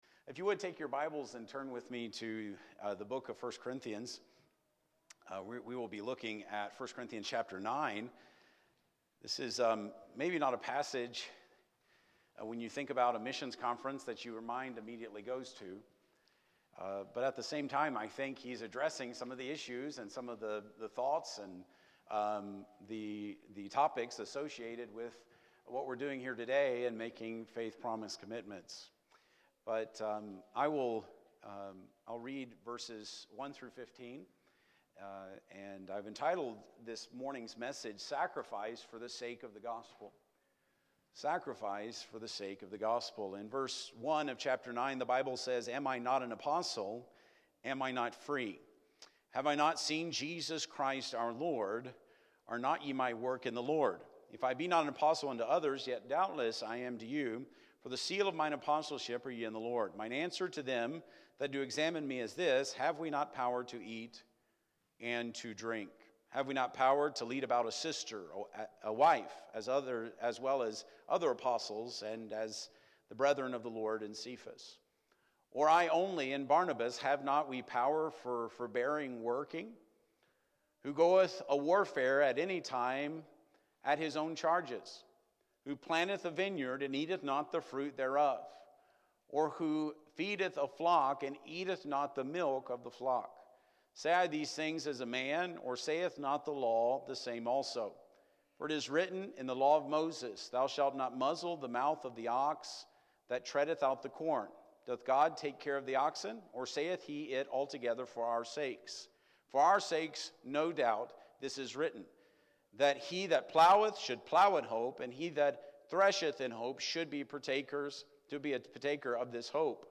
Sermons preached at Mt. Zion Baptist Church in St. Clair, Missouri.